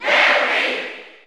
Category: Crowd cheers (SSBU) You cannot overwrite this file.
Larry_Cheer_German_SSBU.ogg.mp3